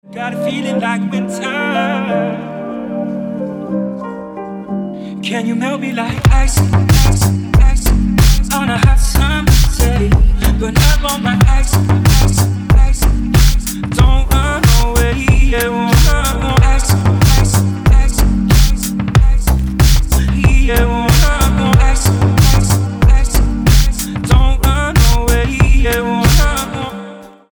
• Качество: 320, Stereo
красивые
Electronic
спокойные